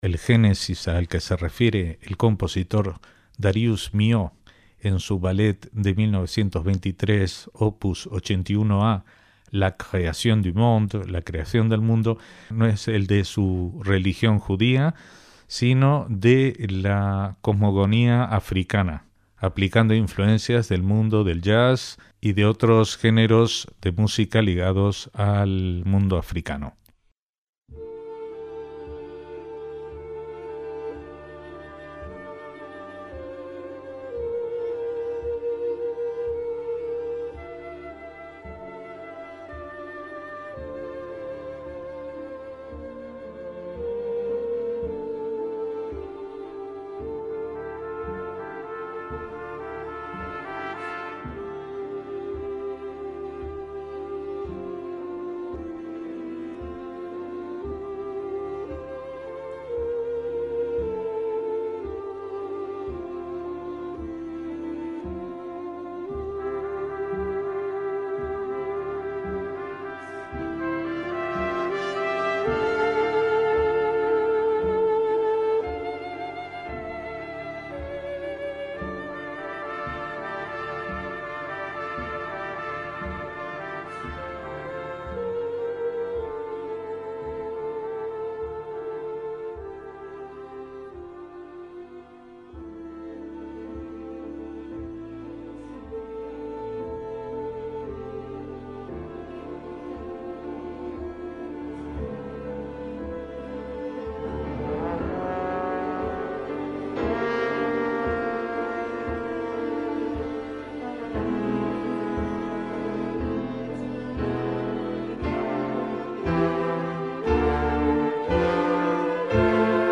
MÚSICA CLÁSICA
utilizó elementos del jazz